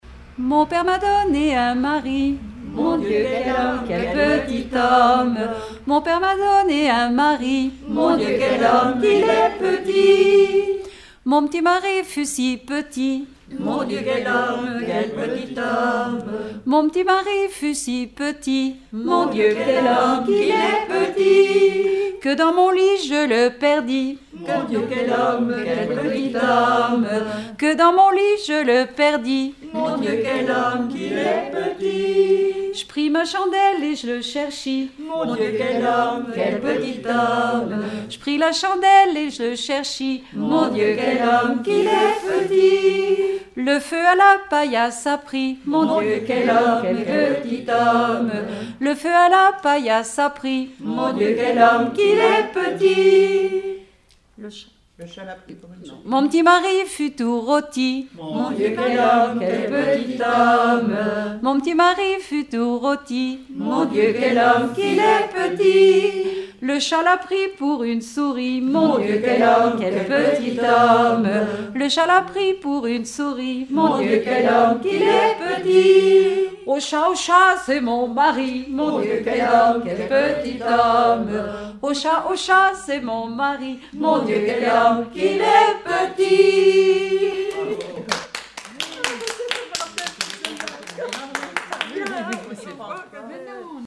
Genre laisse
Rassemblement de chanteurs
Pièce musicale inédite